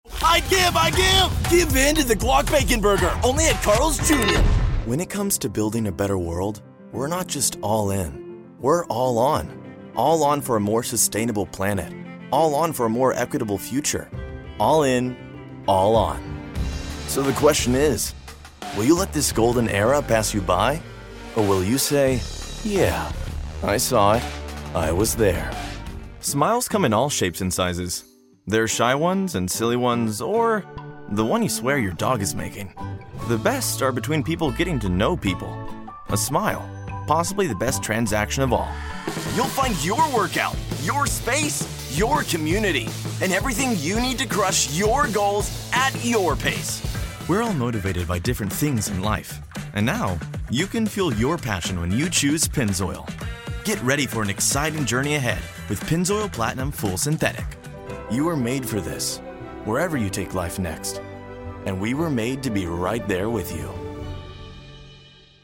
Teenager (13-17) | Yng Adult (18-29)